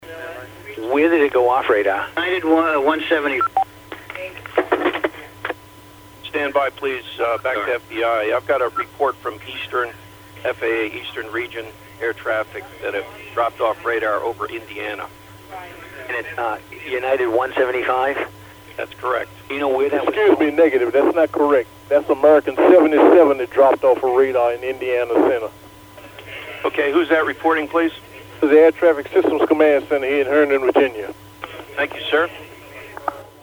That segment of the conversation via the Tactical Net can be heard in this audio.